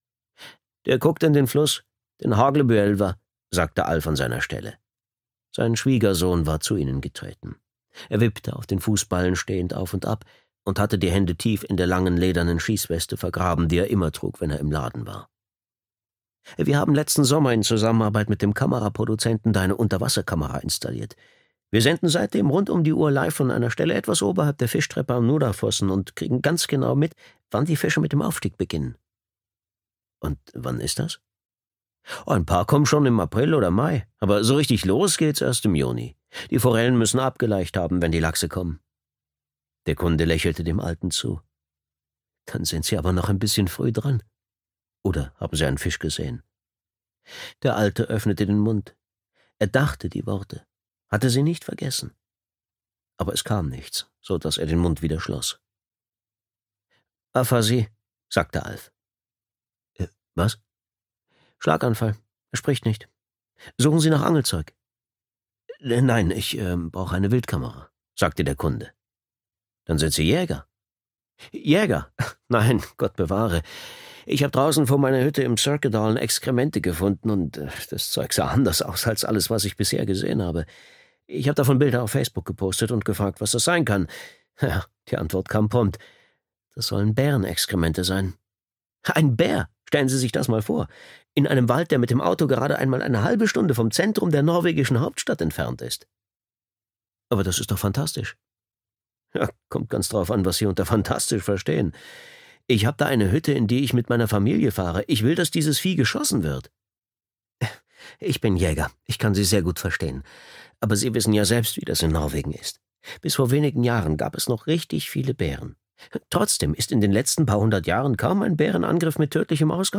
Messer (DE) audiokniha
Ukázka z knihy